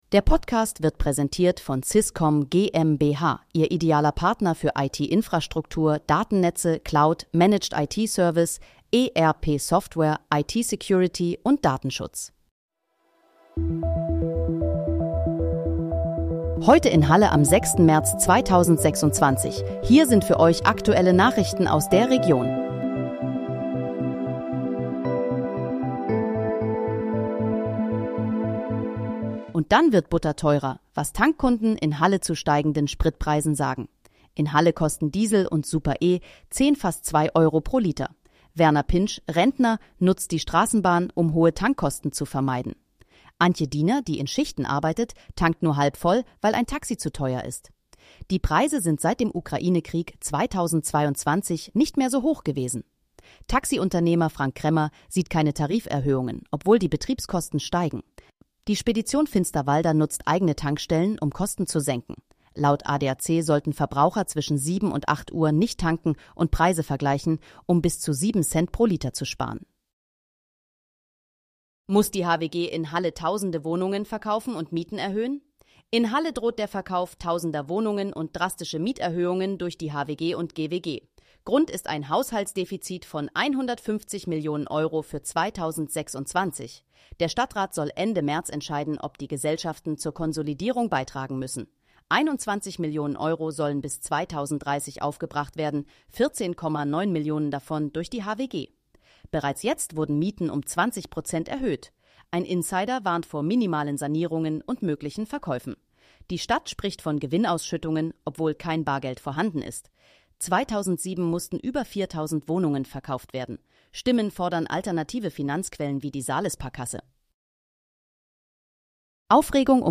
Heute in, Halle: Aktuelle Nachrichten vom 06.03.2026, erstellt mit KI-Unterstützung